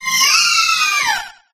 alcremie_ambient.ogg